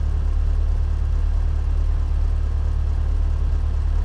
v8_12_idle.wav